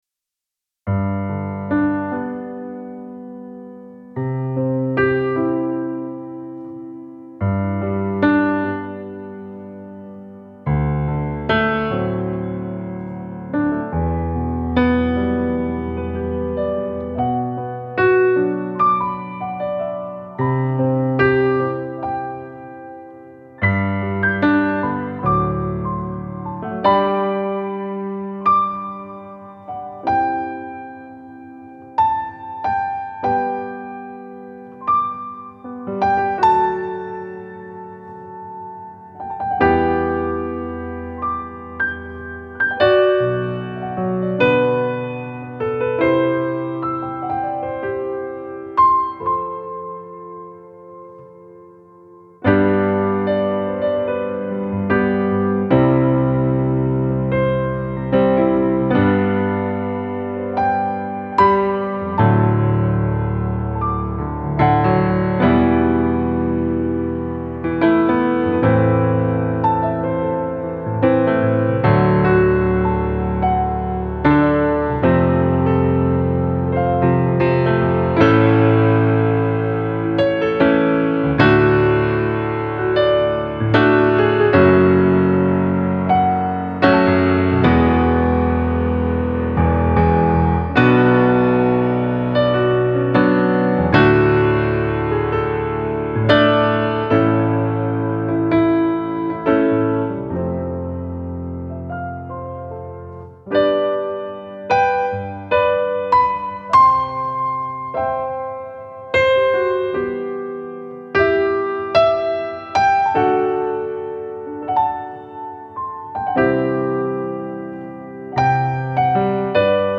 ピアノトラック・デモ
ゆったりとした曲調でバラード風のメロディラインが特徴のピアノ曲です。